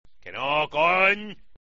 Los sonidos del maquinillo